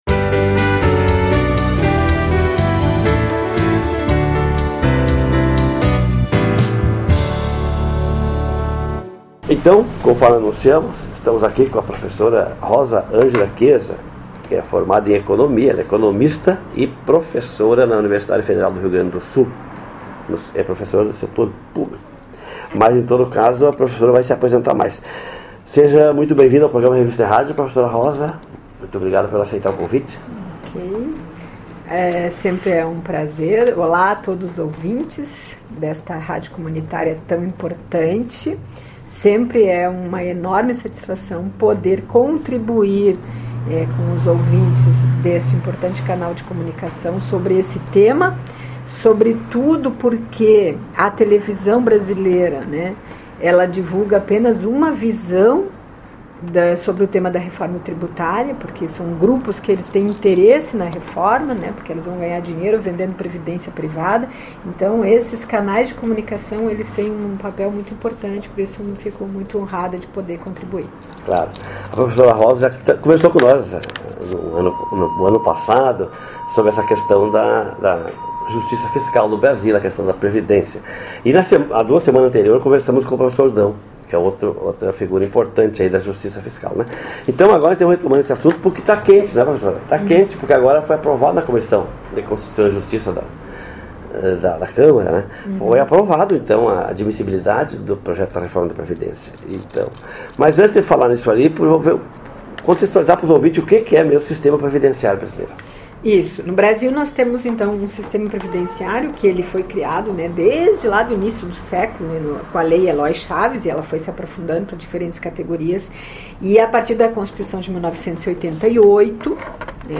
4- Entrevista: Hoje continuamos com a nossa série de programas sobre a Reforma da Previdência.